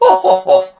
barley_kill_03.ogg